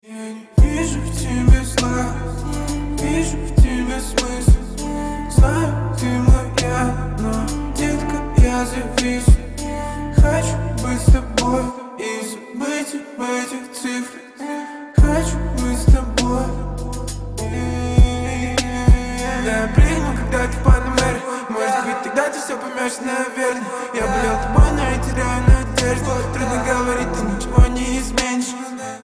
• Качество: 128, Stereo
лирика
басы